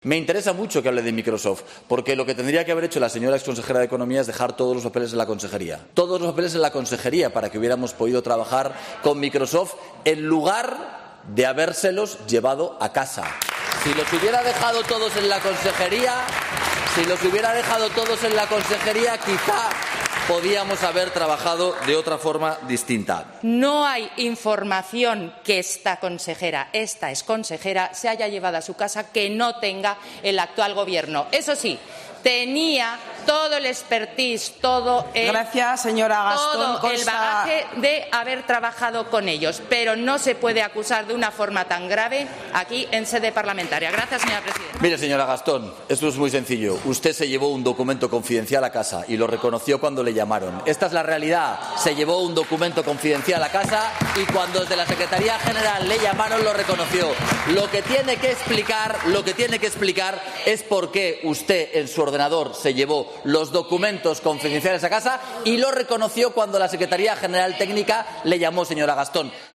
Momento en el que Azcón acusa a Gastón de haberse llevado documentos confidenciales a casa.
Hoy la temperatura en el salón de plenos de las Cortes de Aragón ha subido bastantes grados cuando el presidente Jorge Azcón, ha acusado a la ex consejera de Economía, Marta Gastón, de llevarse a su casa documentos confidenciales sobre las negociaciones del Gobierno regional con la multinacional Microsoft para instalar un campus de centros de datos en Aragón.